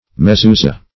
Mezuza \Me*zu"za\, Mezuzah \Me*zu"zah\, Mezuzoth \Mez"u*zoth\,